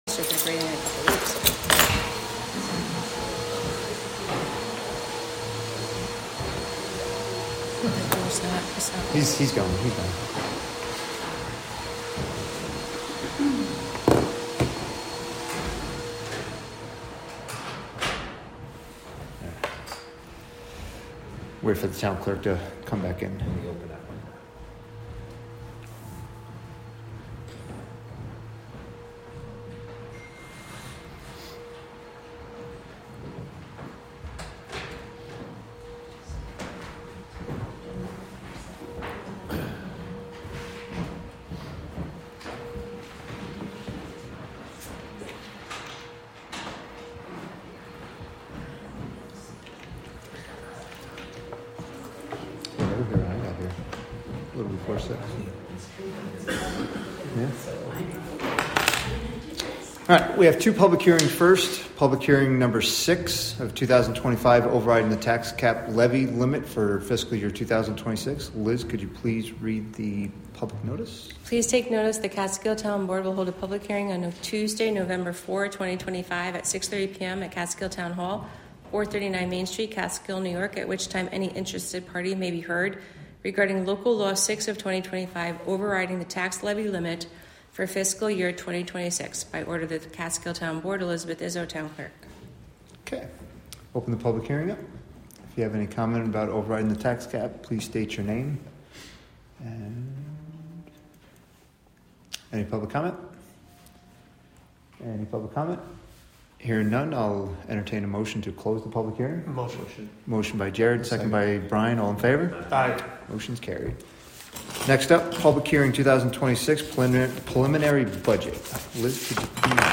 Wave Farm | Live from the Town of Catskill: November 4, 2025 Catskill Town Board Meeting Public Hearings
Live from the Town of Catskill: November 4, 2025 Catskill Town Board Meeting Public Hearings (Audio)